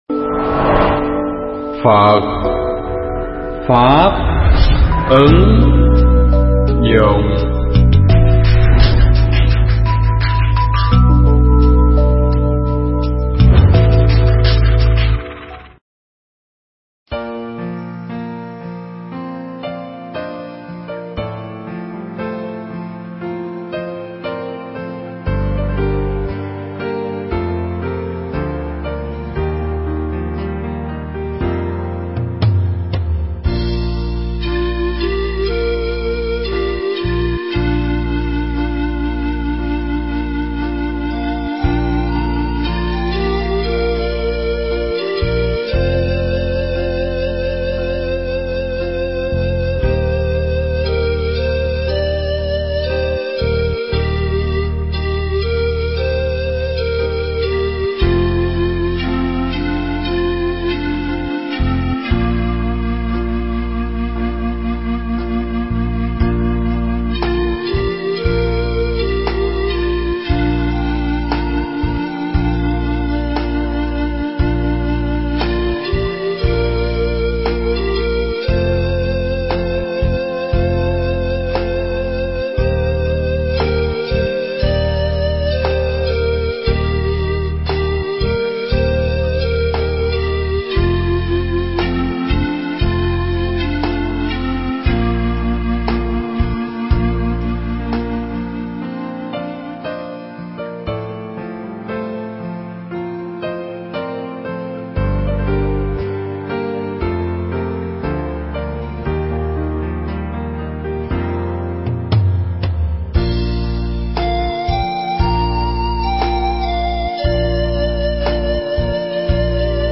Bài thuyết pháp Bông Hồng Dâng Cha